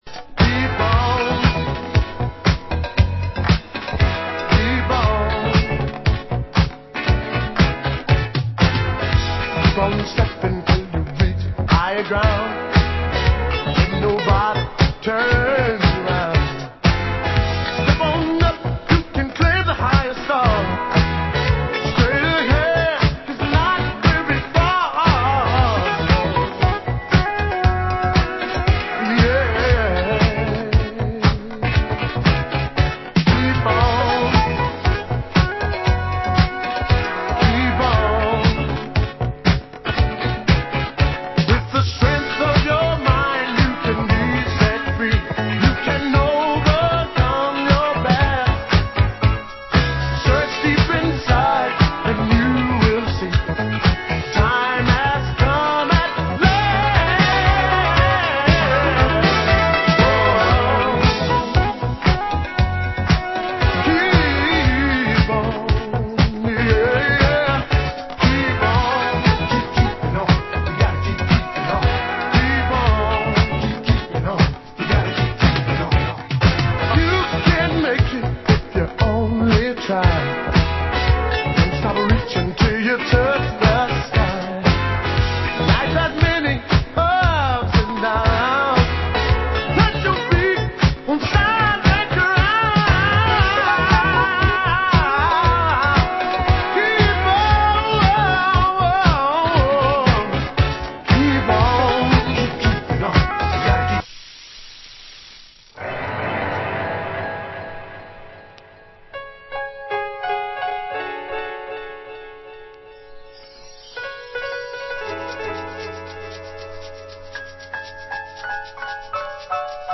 Genre Disco